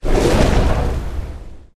Подводный запуск торпеды с корабля